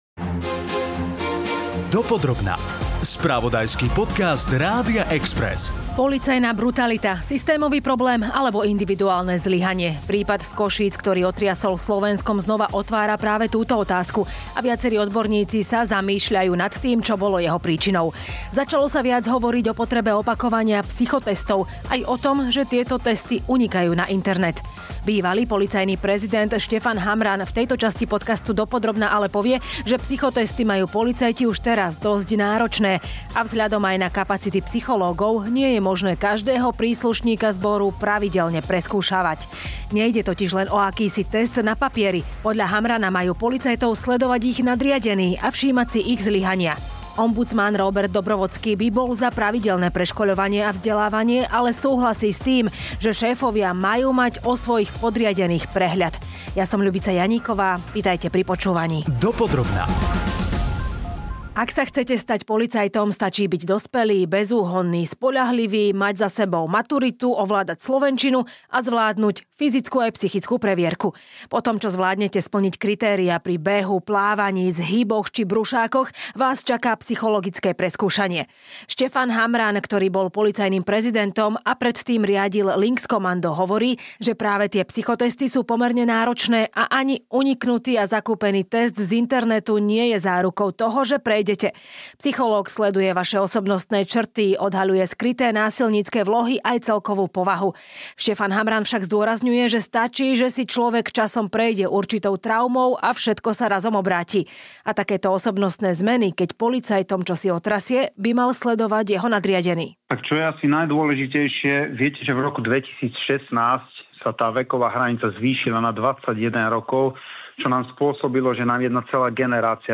Začalo sa viac hovoriť o potrebe opakovania psychotestov aj o tom, že tieto testy unikajú na internet. V podcaste Dopodrobna sa k tomu vyjadrí bývalý policajný prezident Štefan Hamran a ombudsman Róbert Dobrovodský.